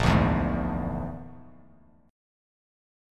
A#7b5 chord